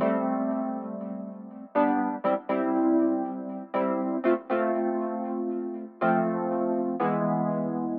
31 ElPiano PT 1+2.wav